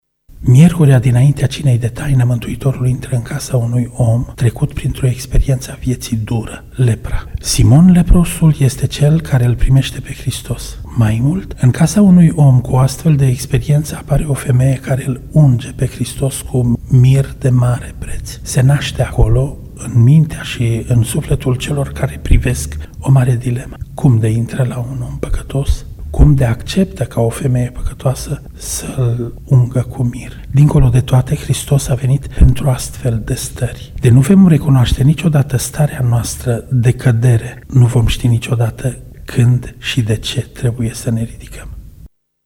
Acest lucru îi revoltă pe cei prezenţi însă semnificaţia gestului este mult mai profundă ne spune preotul ortodox din Tg Mureş